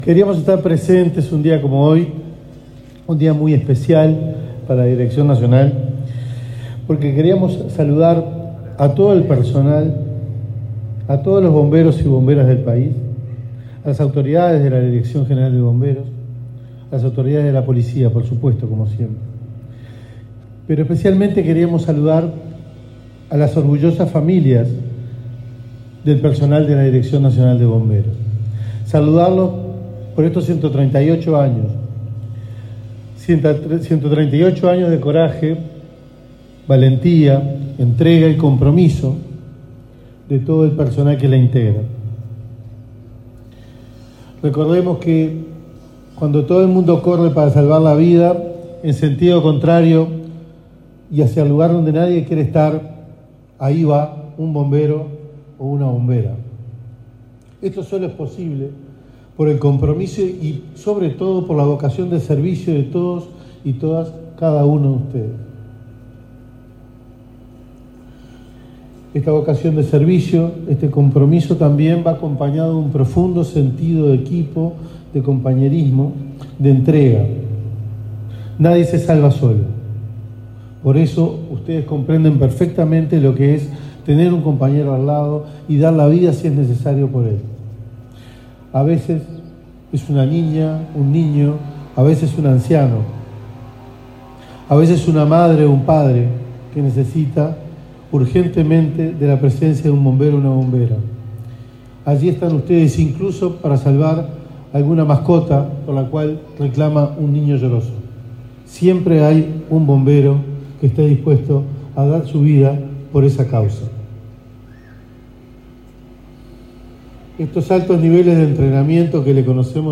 El ministro del Interior, Carlos Negro, se expresó durante el acto conmemorativo del 138.° aniversario de la Dirección Nacional de Bomberos.